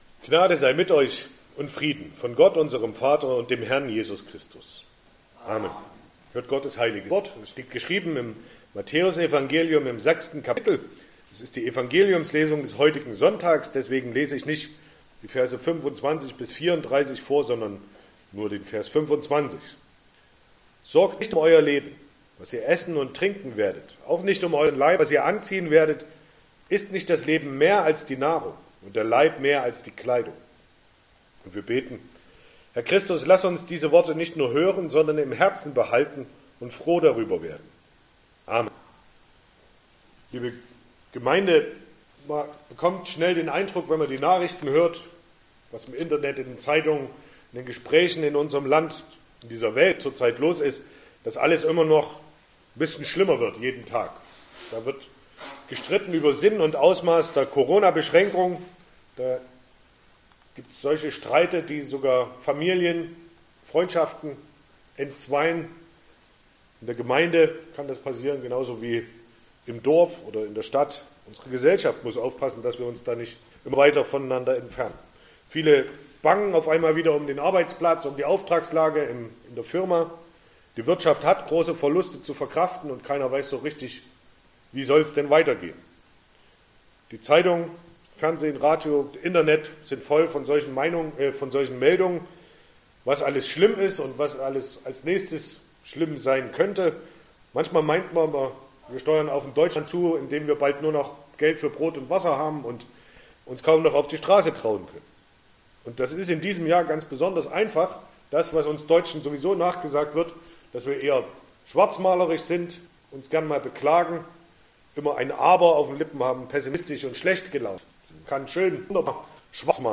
Sonntag nach Trinitatis Passage: Matthäus 6,24-34 Verkündigungsart: Predigt « 16.